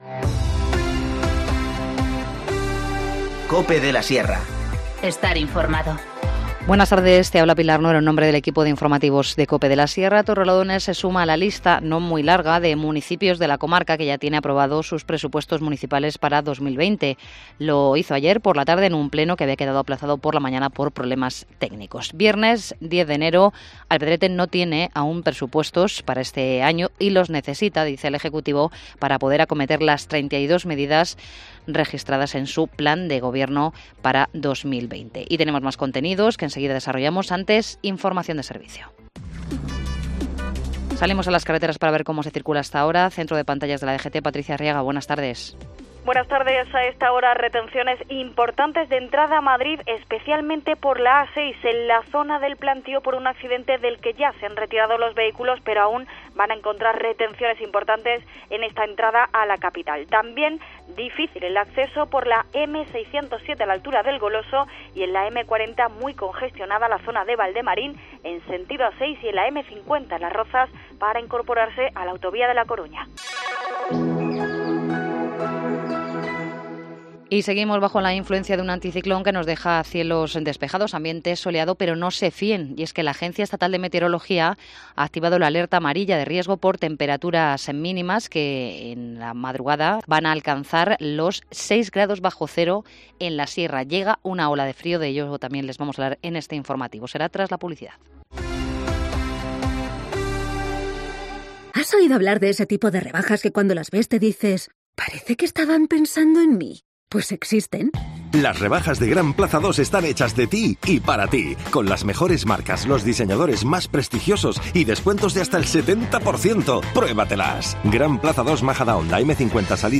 Informativo Mediodía 10 enero 14:20h